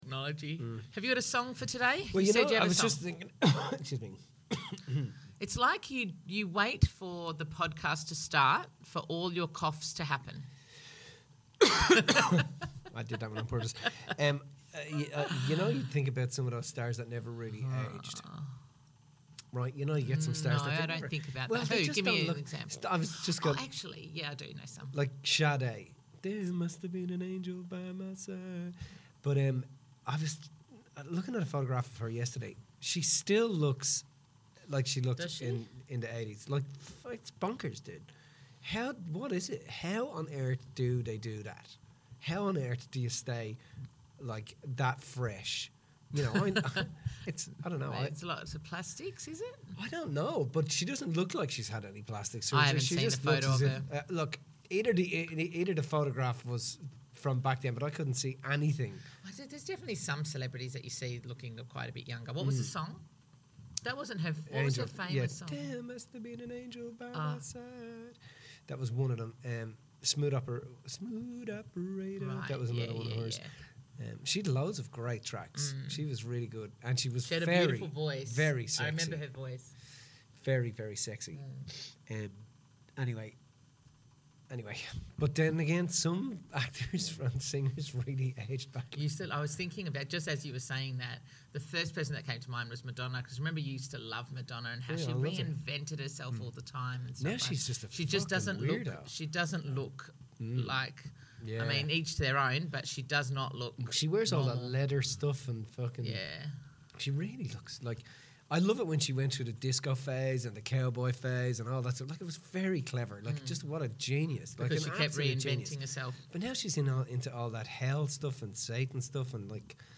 Tune in for a thought-provoking conversation that will enrich your understanding of how to connect more deeply with those around you!